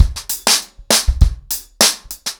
BellAir-A-100BPM__1.5.wav